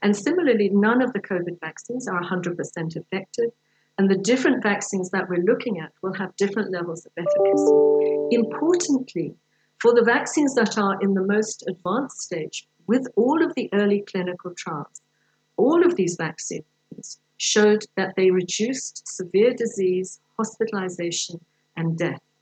During a parliamentary briefing this week, Rees said getting vaccinated would also help reduce pressure on the country’s healthcare system.